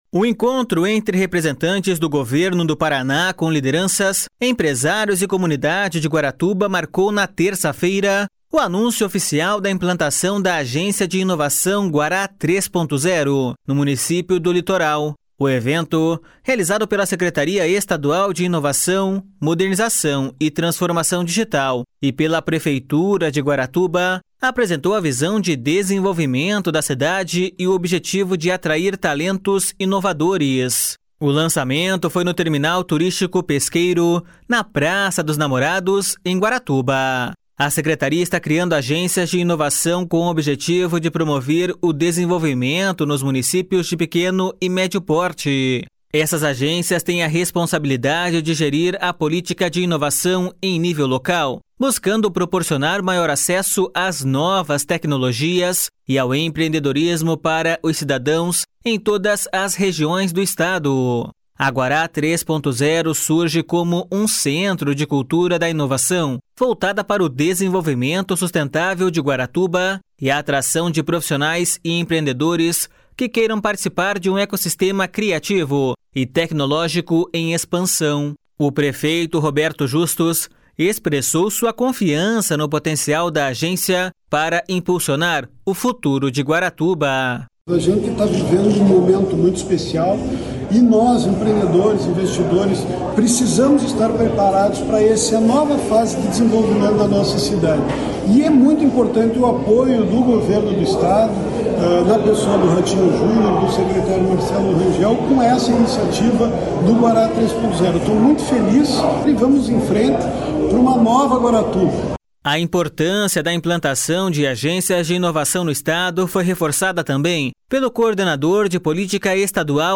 O prefeito Roberto Justus expressou sua confiança no potencial da agência para impulsionar o futuro de Guaratuba.// SONORA ROBERTO JUSTUS.//